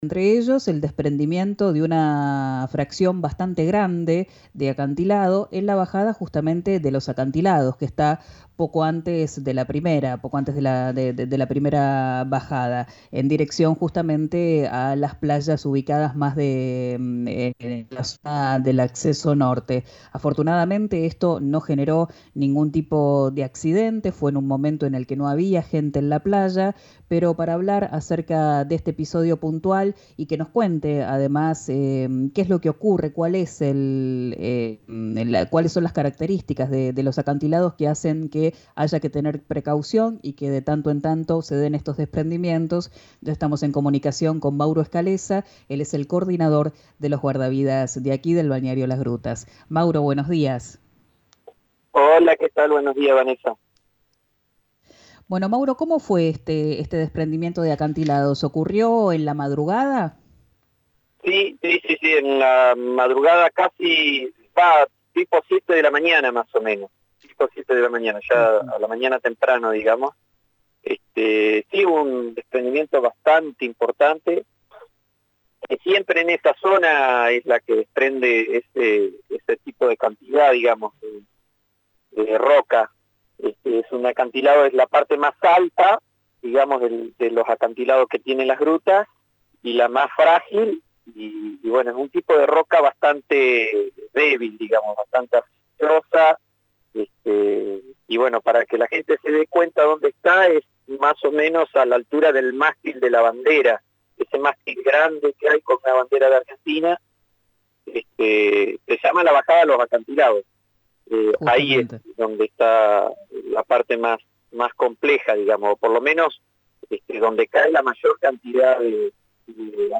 En comunicación con «Quien dijo verano», por RÍO NEGRO RADIO, recomendó no acercarse a la Bajada de los Acantilados por la probabilidad de desprendimientos.